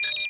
I_Beep.mp3